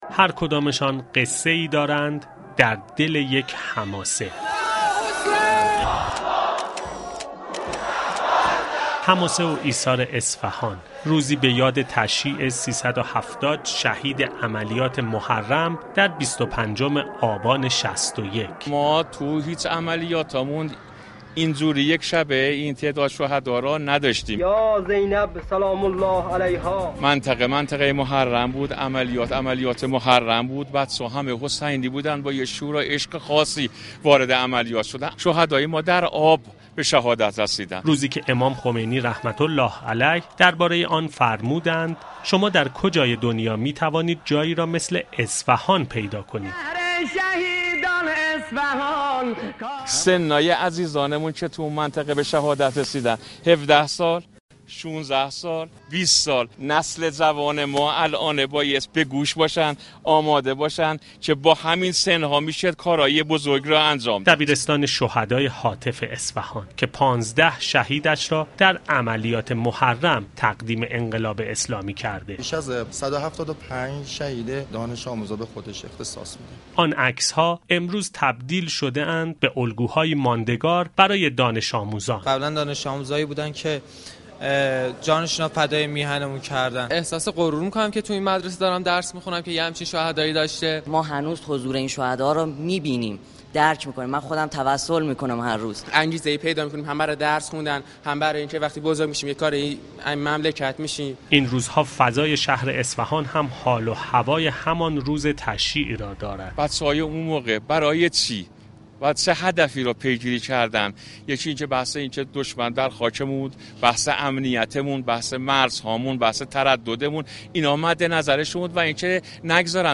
آقای قالیباف در نطق پیش از دستور خود در جلسه علنی امروز مجلس شورای اسلامی با اشاره به تشییع شهدا در چنین روزی در شهر اصفهان یاد و خاطره شهدای بزرگی چون شهید خرازی و شهید ردانی‌پور را گرامی داشت.